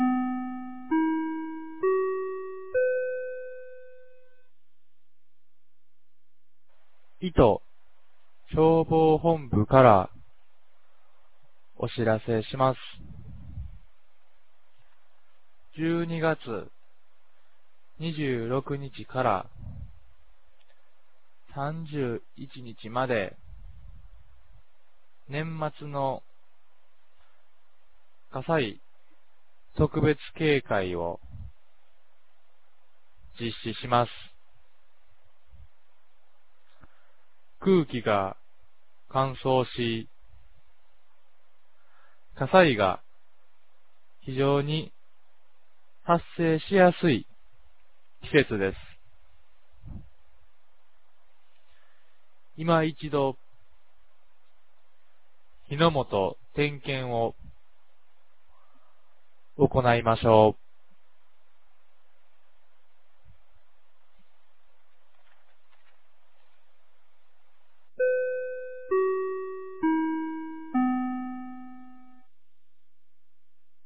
2024年12月23日 10時01分に、九度山町より全地区へ放送がありました。